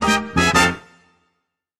Music Effect; Latin Jazz Band Hits.